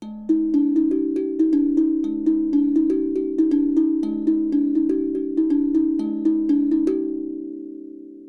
HAPI Drum
丸い金属製のボディにスリットが空いており手やマレットで叩いて音を出す楽器ですが、アタック音とその響きの両方が特徴的で、イメージ通りレコーディングするのは少し骨の折れる楽器でもあります。
次にXYで録ってみます。位置は50cmくらいです。
XYステレオ
誇張しすぎない、自然な広がり感が出ていると思います。
hapi-XY-close.wav